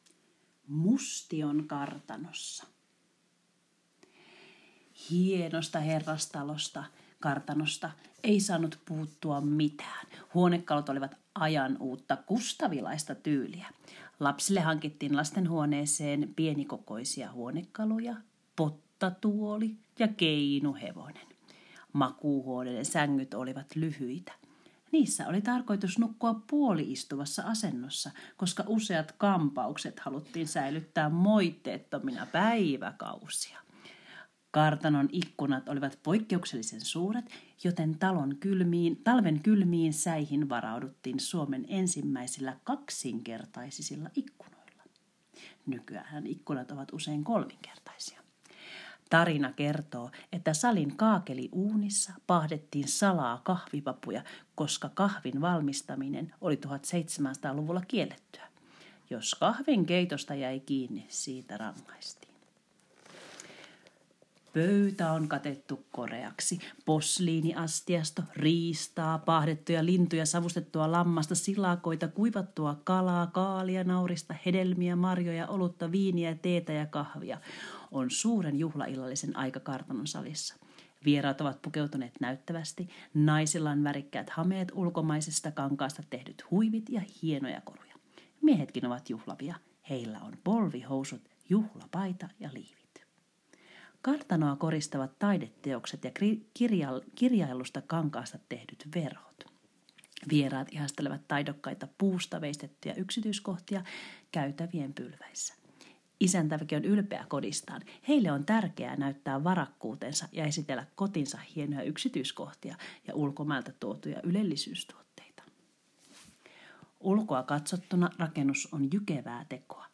1. Kuuntele, mitä opettaja kertoo 1700-luvun elämästä Mustion kartanossa.